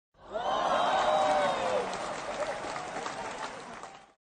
Crowd - Wooooo